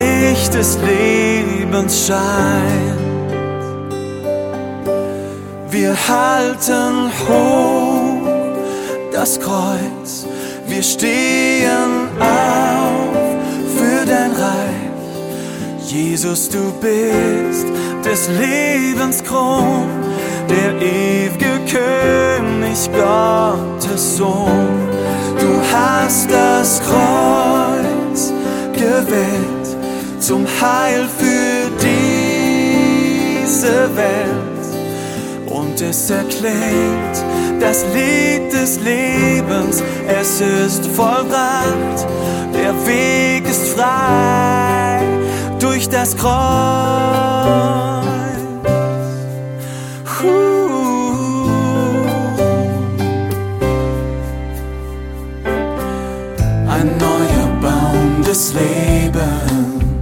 Worship 0,99 €